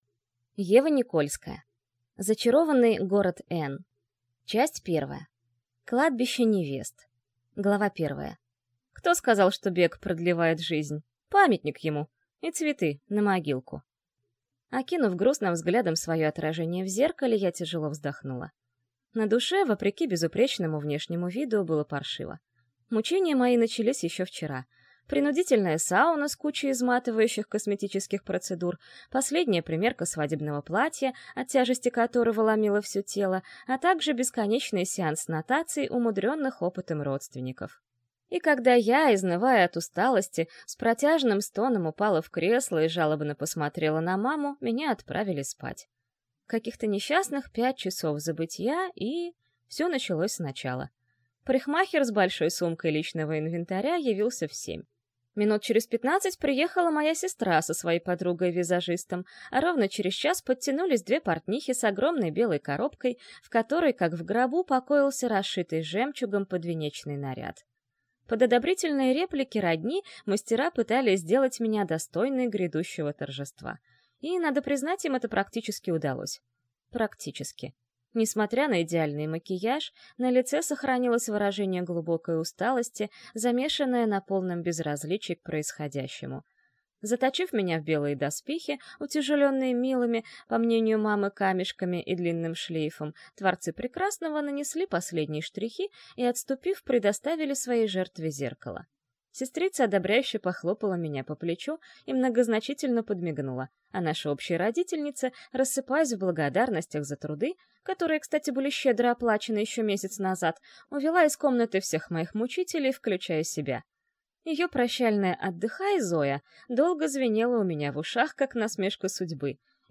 Аудиокнига Зачарованный город N - купить, скачать и слушать онлайн | КнигоПоиск